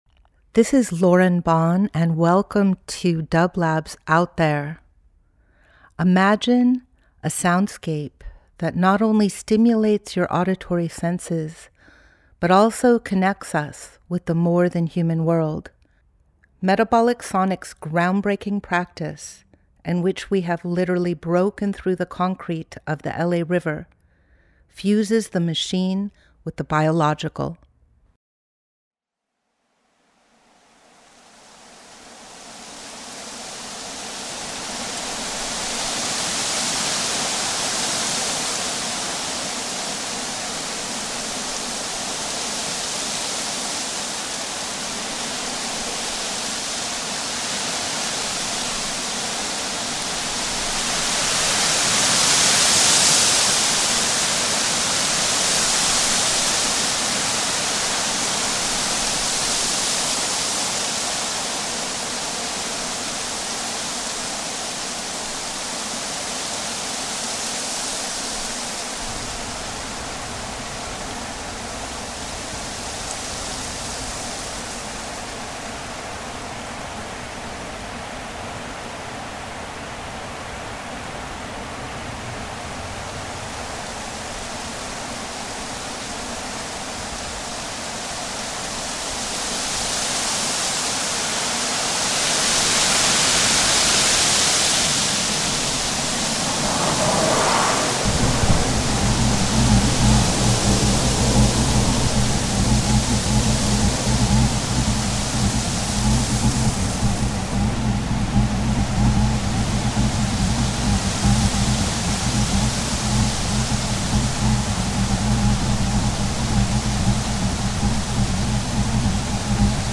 Each week we present a long-form field recording that will transport you through the power of sound.
Pine needles vibrating in the wind. Test flights out of Edwards Air Force Base. Telephone poles conducting Aeolian phenomena. Pulsating transmission towers. Coyotes and crows warning of an oncoming earthquake. An empty silo reverberating on the edge of Owens Dry Lake.
A soundscape composed of field recordings taken from the Owens Valley in 2020, reimagined today.